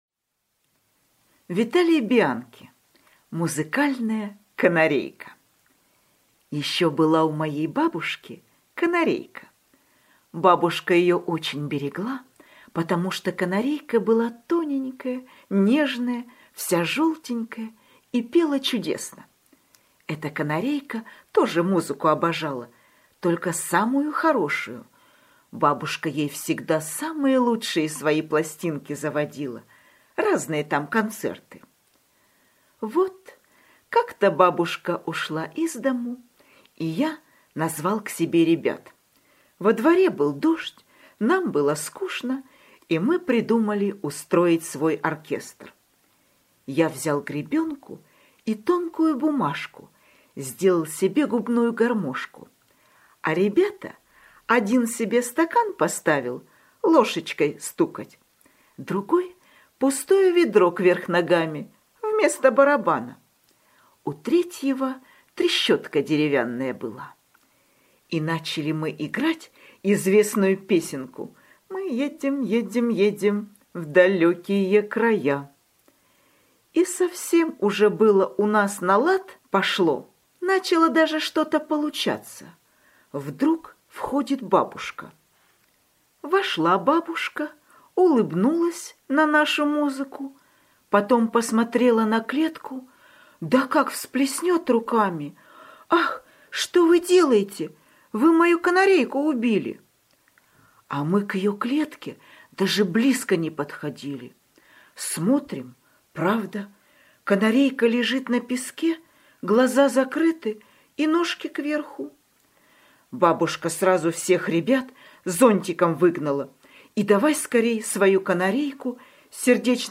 Аудиорассказ «Музыкальная канарейка»